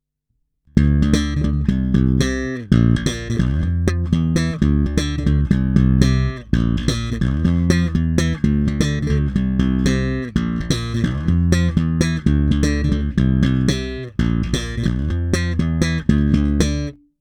Pevný, kovově vrnící, poměrně agresívní, prosadí se.
Není-li uvedeno jinak, následující nahrávky jsou provedeny rovnou do zvukové karty, s plně otevřenou tónovou clonou a bez zařazení aktivní elektroniky.